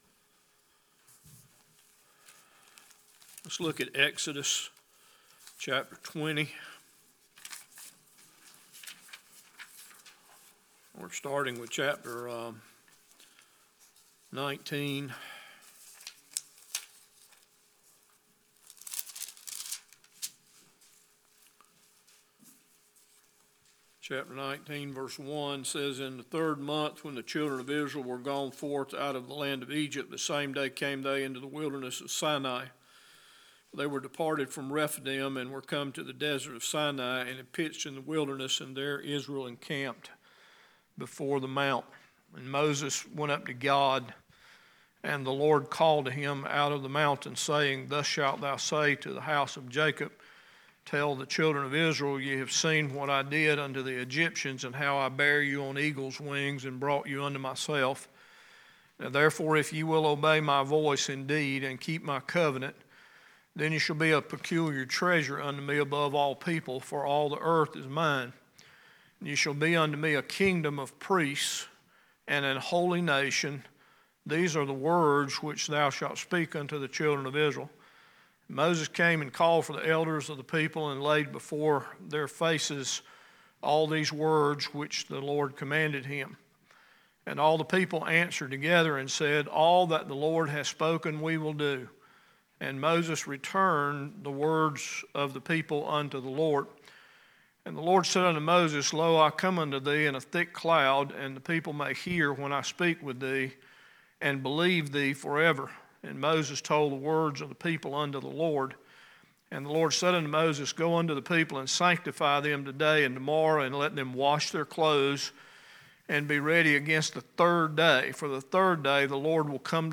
God uses our experiences to shape and mold our character. In this message, we see what the Israelites experience in their journey and how that relates to us.